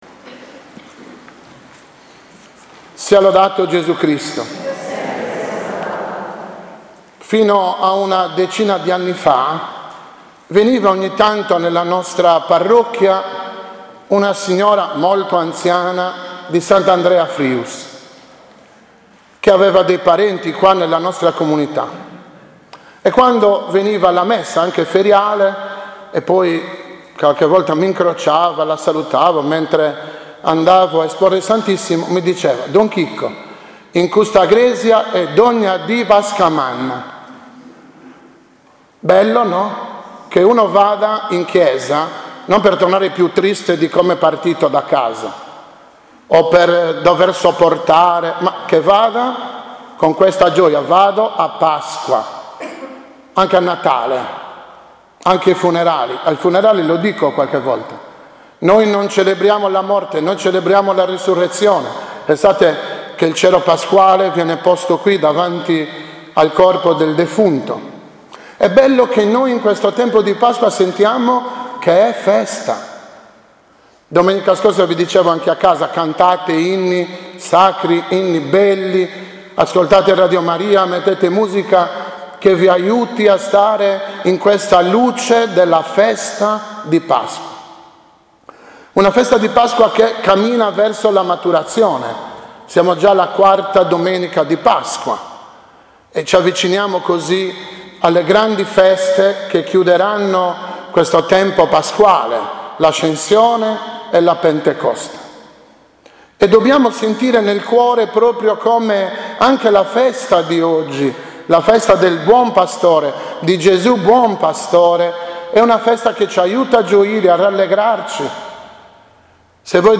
2019 OMELIA DELLA IV DOMENICA DI PASQUA C